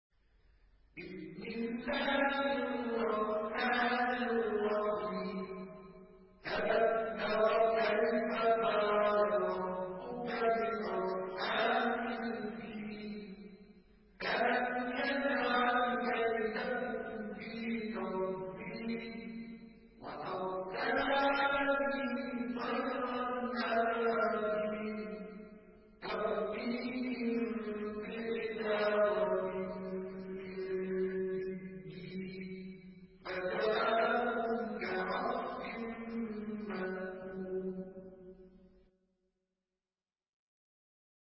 Surah الفيل MP3 by مصطفى إسماعيل in حفص عن عاصم narration.